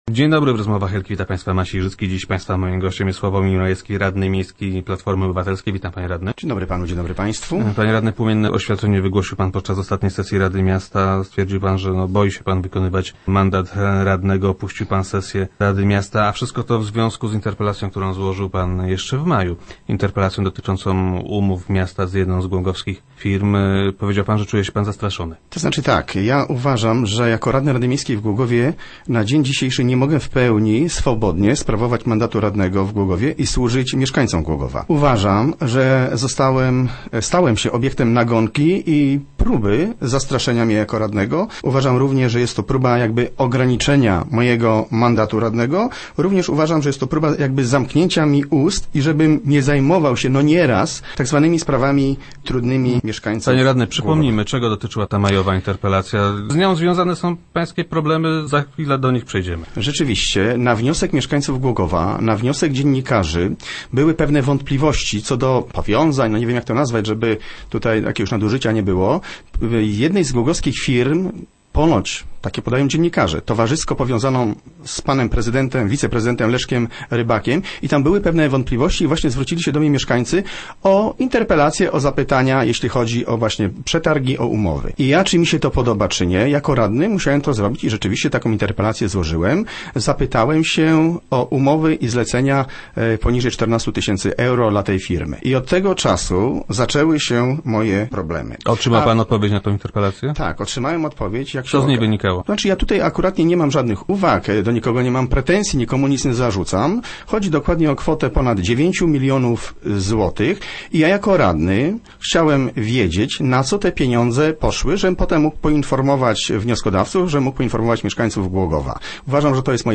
Jak twierdzi, po interpelacji dotyczącej umów gminy z jedną z głogowskich firm, zaczął mieć problemy. Radny Majewski był gościem czwartkowych Rozmów Elki.